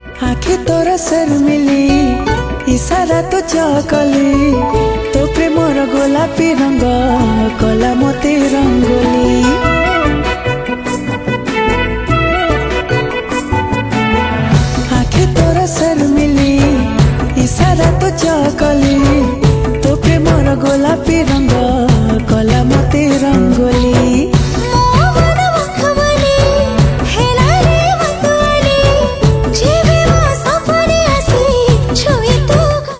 Odia Album Ringtones
Dance song